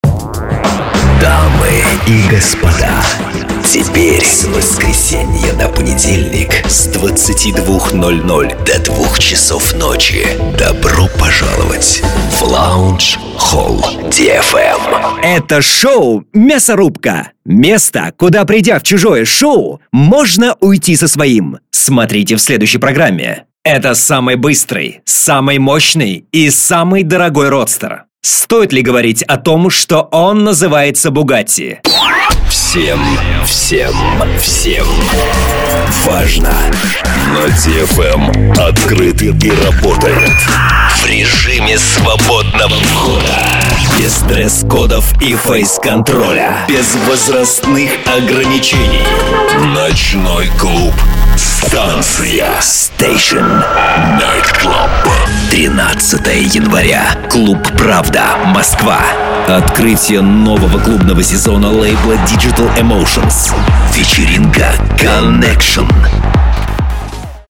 Тракт: rode, akg, shure, tlaudio, dbx